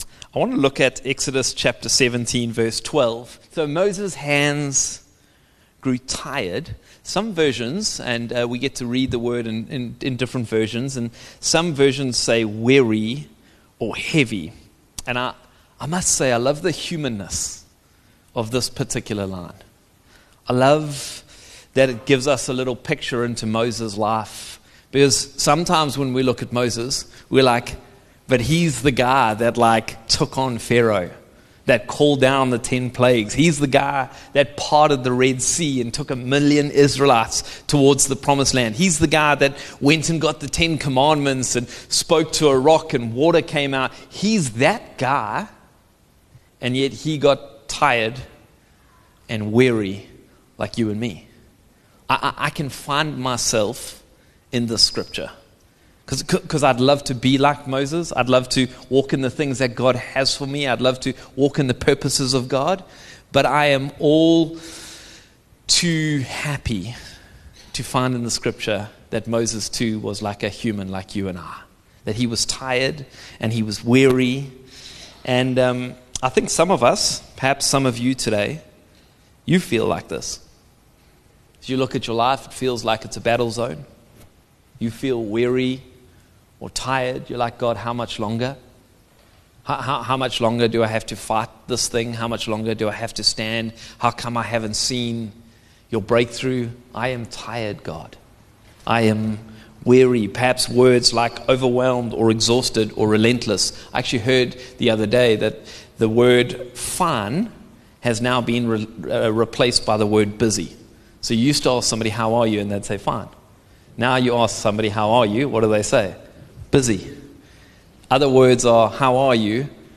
Upper Highway Vineyard Sunday messages